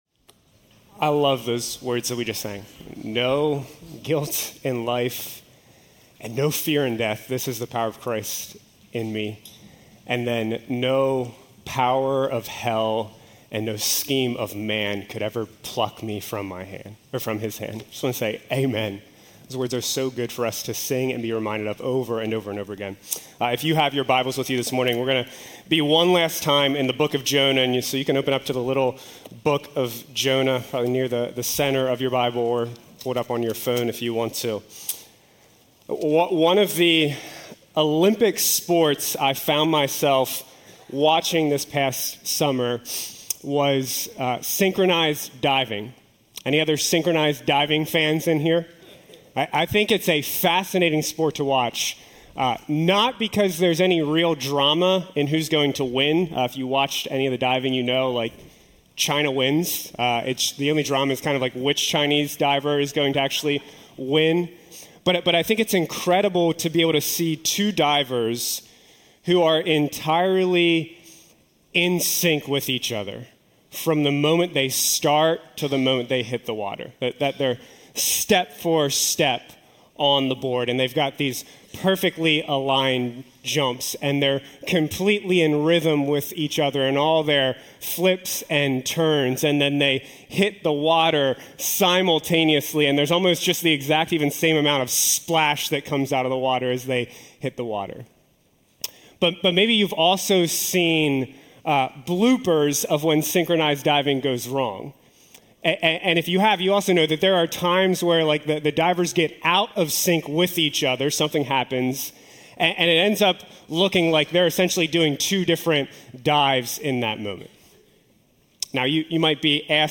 These are sermons delivered during the Sunday morning worship services of Keystone Church, an Evangelical Free Church in Paradise, PA, USA.